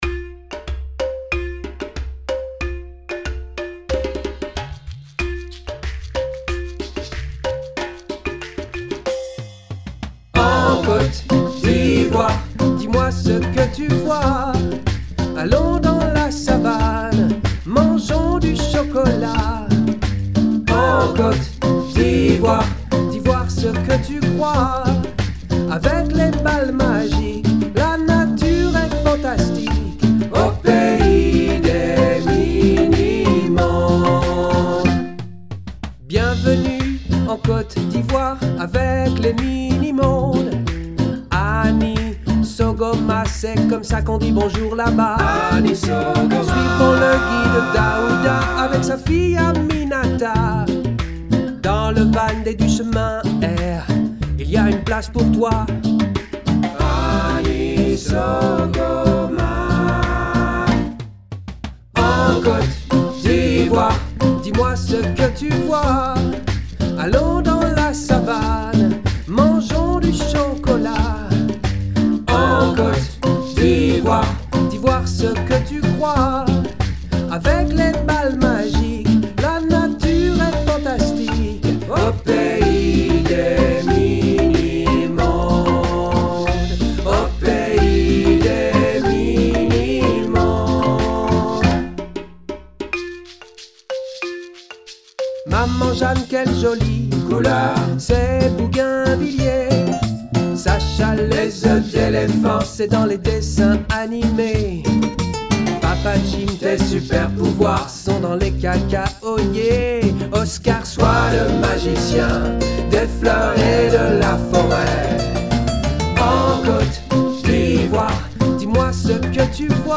Il y a de quoi danser :